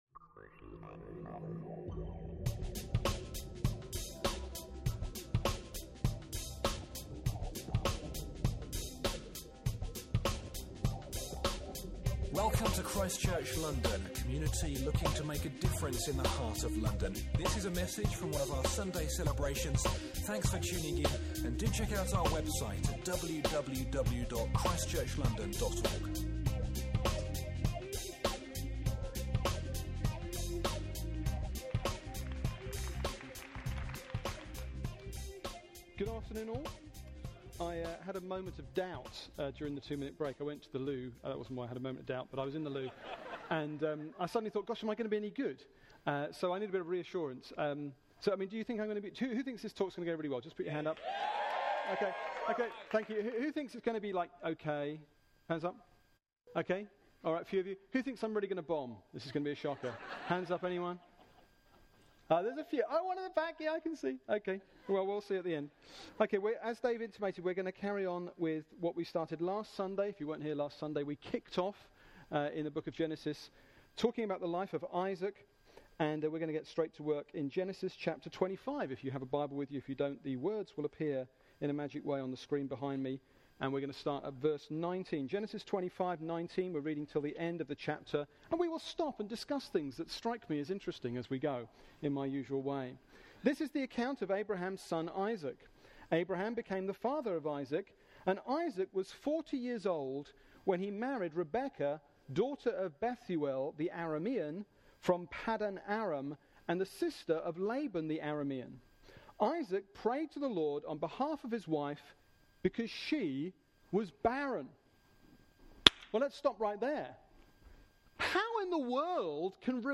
Genesis 25:19-24 – Preaching from ChristChurch London’s Sunday Service